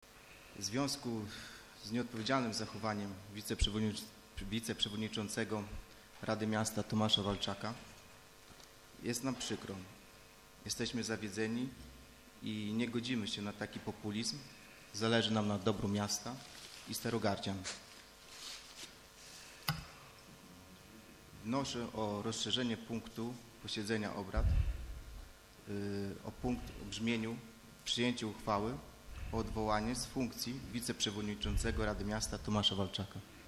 Bardzo zaskakujący przebieg miała wczorajsza sesja Rady Miasta.